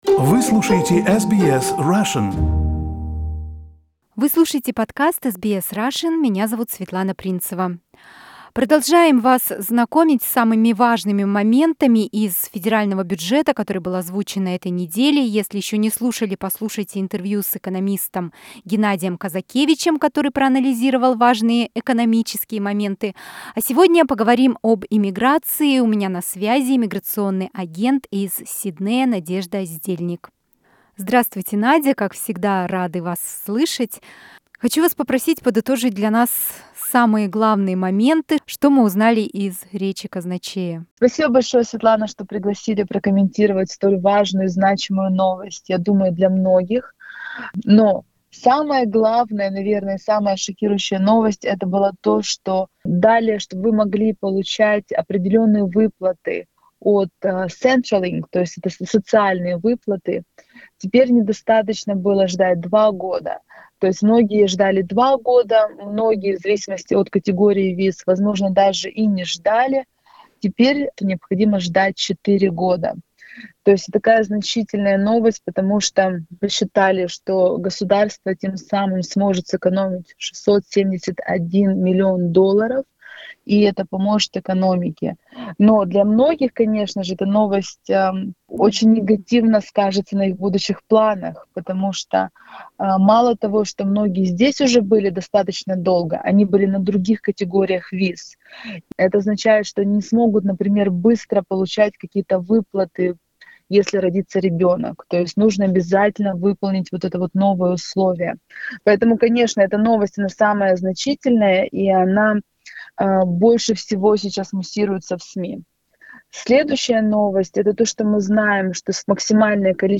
This interview is available in Russian only.